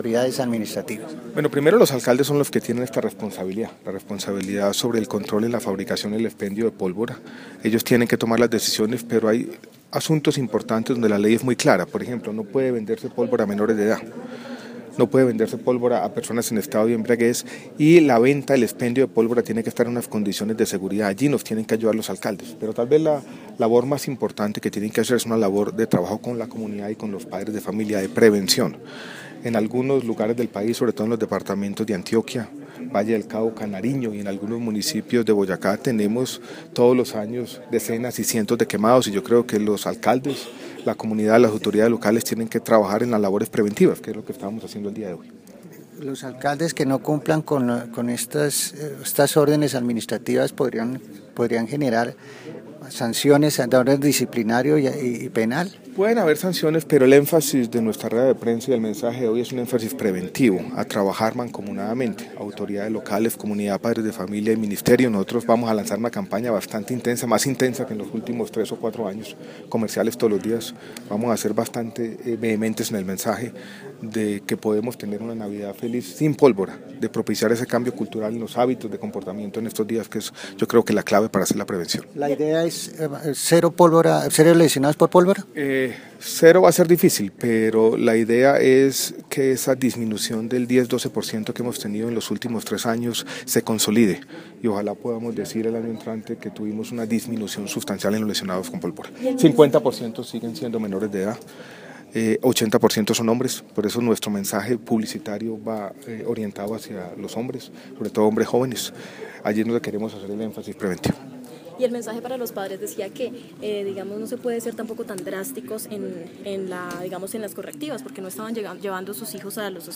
Audio, Mensaje del Ministro Gaviria frente al uso de la pólvora en todo el país.